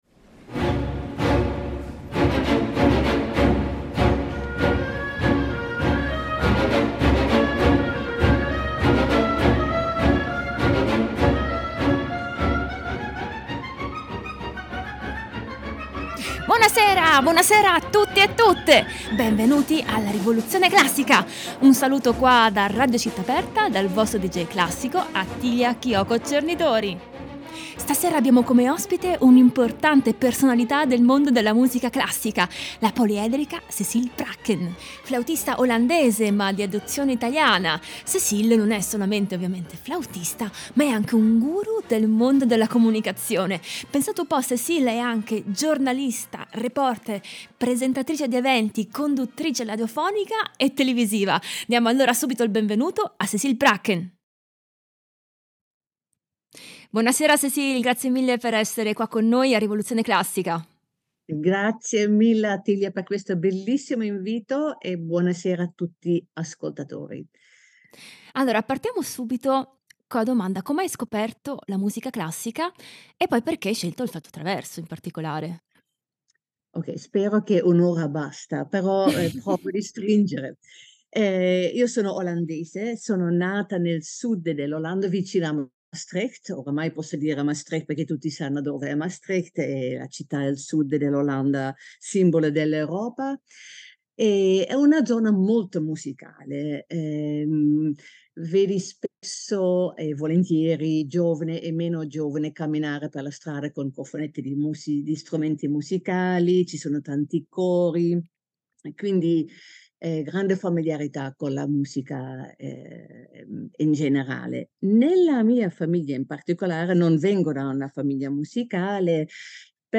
per flauto e chitarra
Fantasia in do maggiore per flauto e organo
per flauto solo